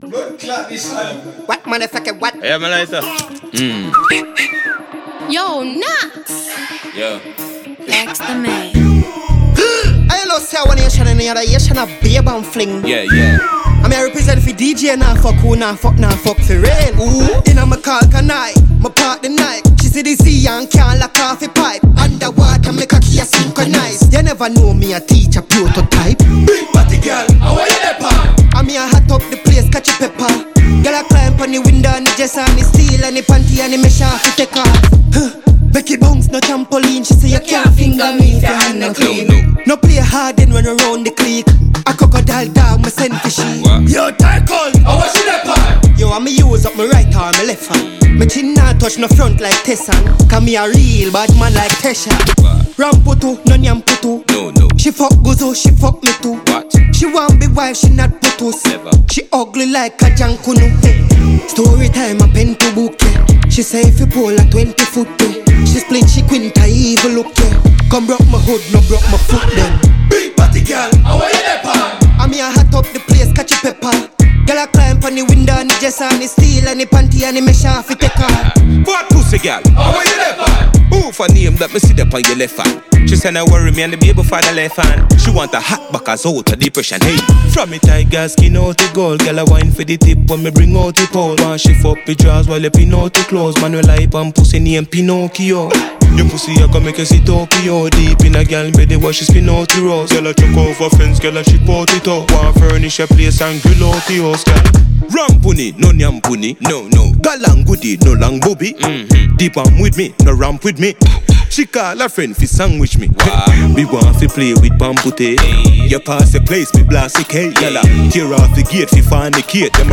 Check out this new tune from Jamaican dancehall artiste